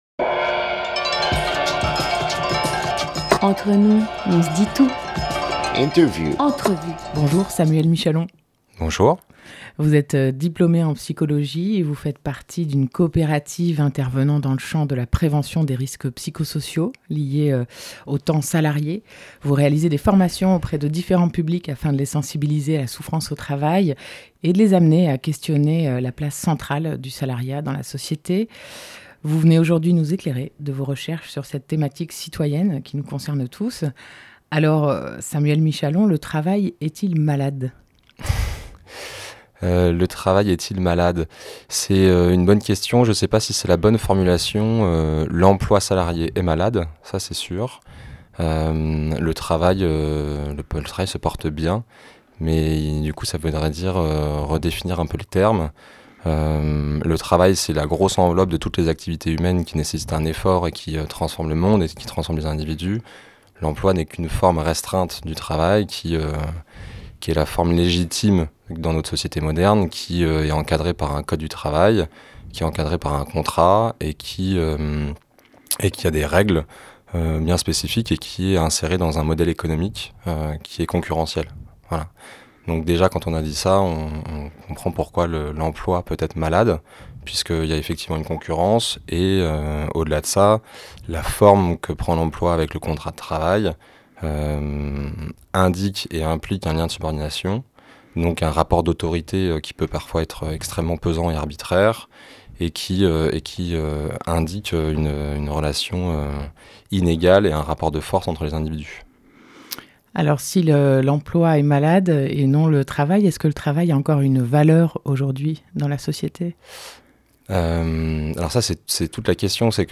17 novembre 2017 16:31 | Interview